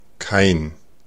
Ääntäminen
Synonyymit null Ääntäminen Tuntematon aksentti: IPA: /kaɪ̯n/ Haettu sana löytyi näillä lähdekielillä: saksa Käännöksiä ei löytynyt valitulle kohdekielelle.